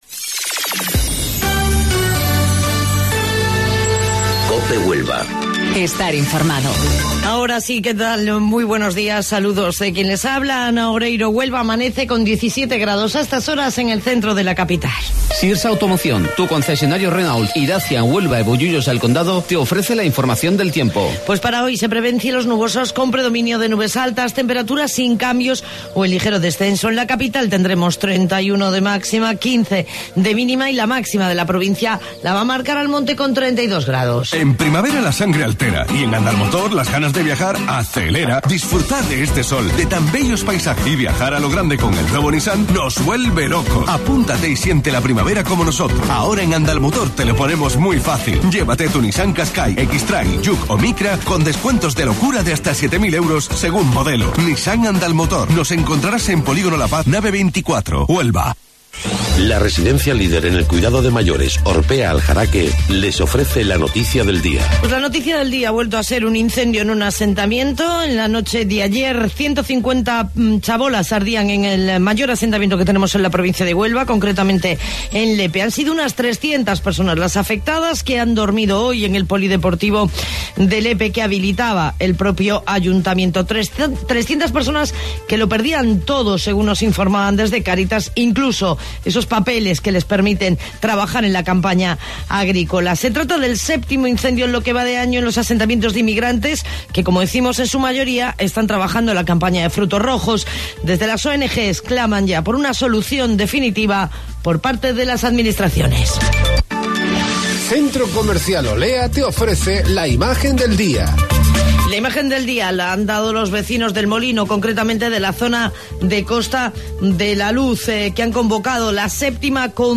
AUDIO: Informativo Local 08:25 del 24 de Mayo